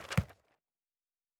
pgs/Assets/Audio/Fantasy Interface Sounds/Book 08.wav at master